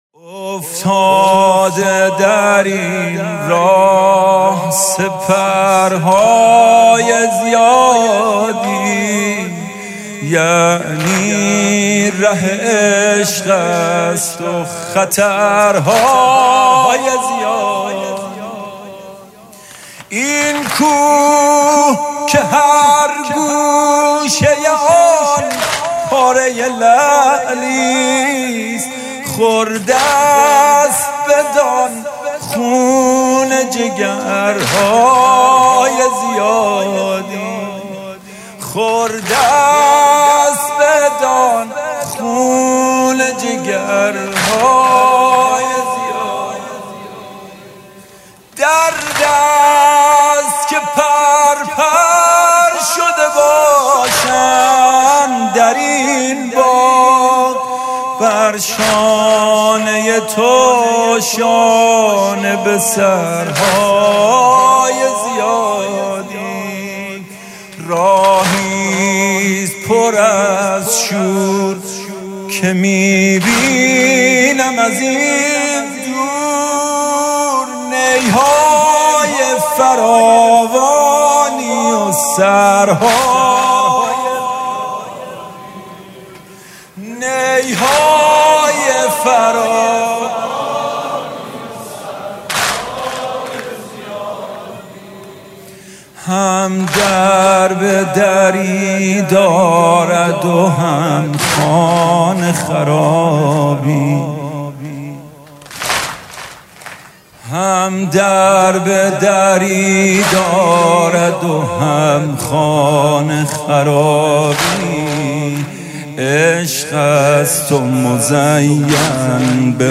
music-icon واحد: ره عشق است و خطرهای زیادی